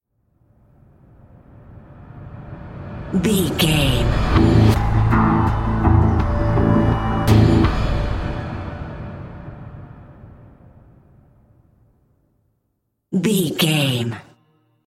Aeolian/Minor
synthesiser
drum machine
ominous
dark
suspense
haunting
creepy